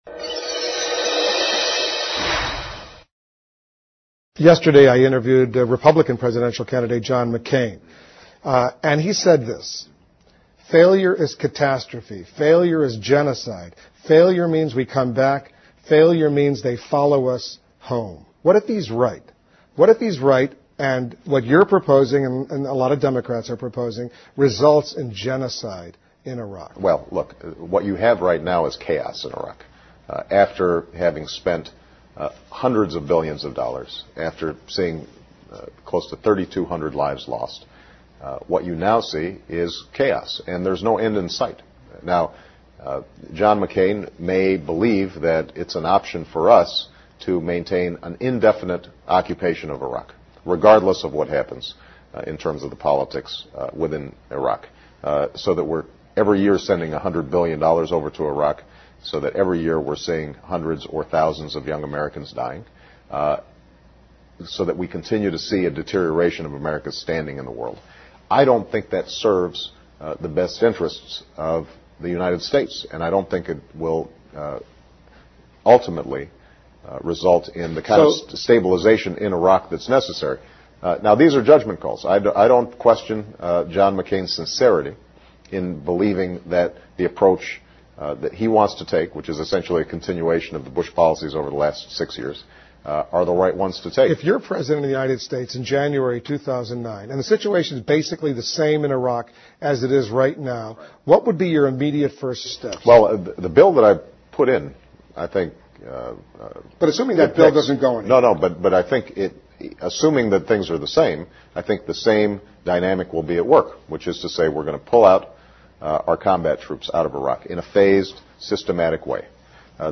访谈录 Interview 2007-04-06&08, 专访即将诞生的第一位黑人总统 听力文件下载—在线英语听力室